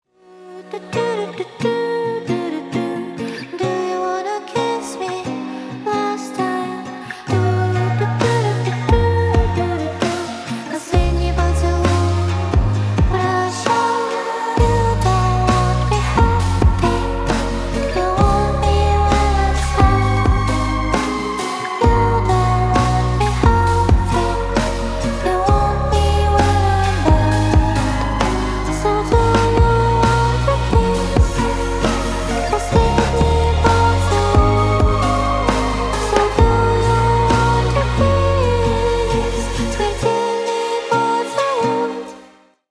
• Качество: 320, Stereo
женский вокал
спокойные
красивый женский голос